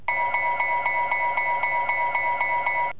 GE 25 tonner Bell
light_bell.wav